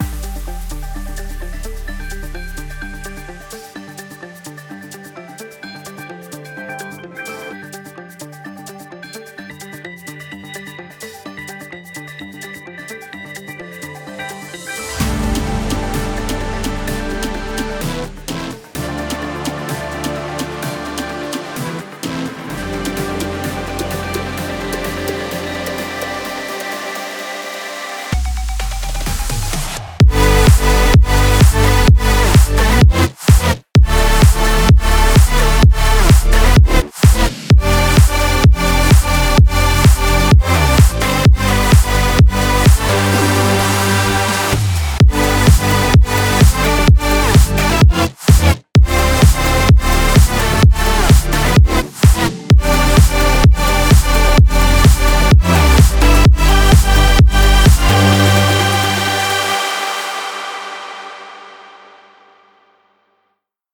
- MakeTrack - EDM - חודש 1
כרגע עדיין די במצב סקיצה. הסולם הוא מז’ור מיקסולידיאן - סולם שאני מאד אוהב.